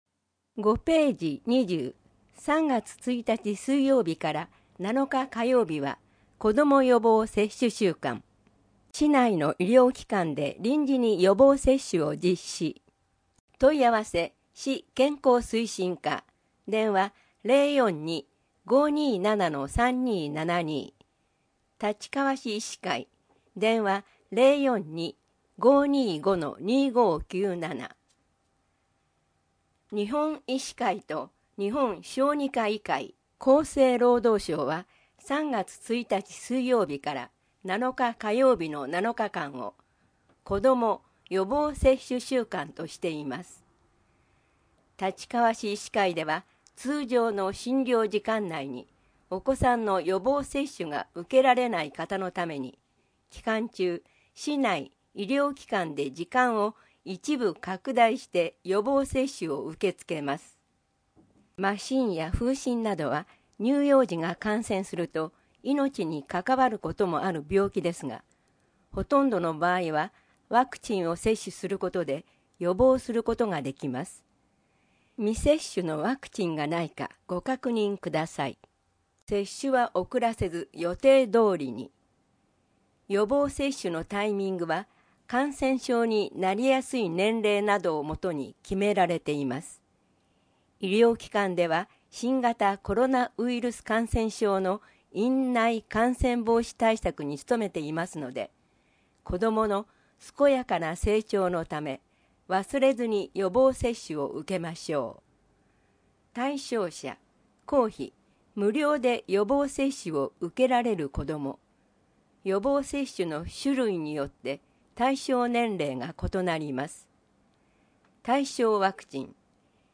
MP3版（声の広報）